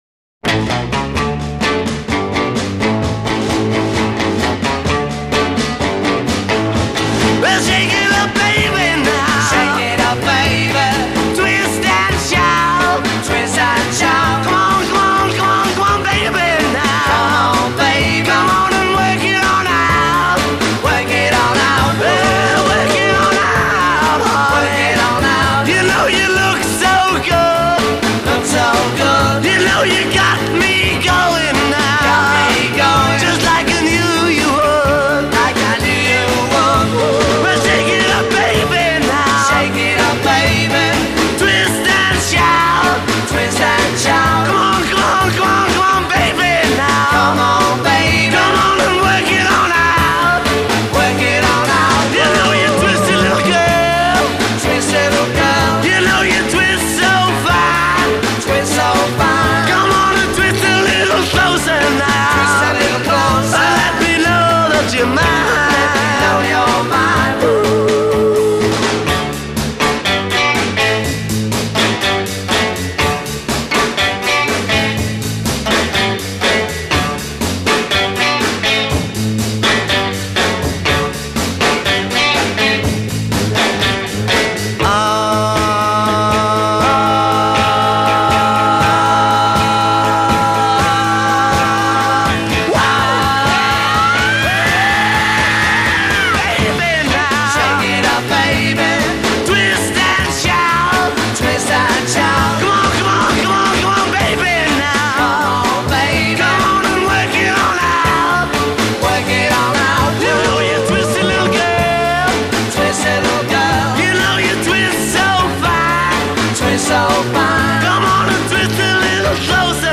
(D maj)
B Bridge : 6 build a 9th chord and tension
B Coda : 5 build chord and end with 9th chord on guitar